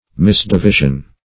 Misdivision \Mis`di*vi"sion\, n. Wrong division.